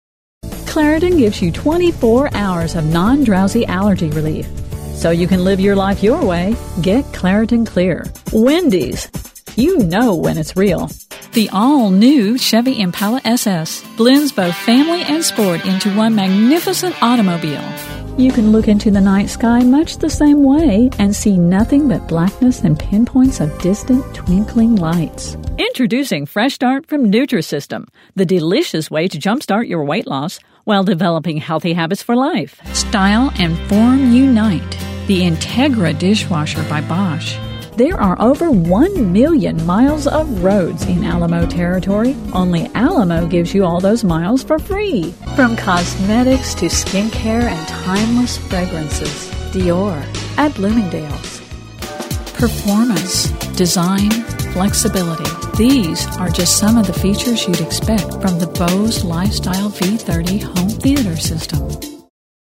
• Upbeat, friendly, conversational, professional, calm, pleasant
• grounded, conversational, explanatory
• Enticing, enthusiatic
• Believable, straight forward upbeat
• Fun, perky